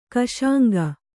♪ kaśaŋga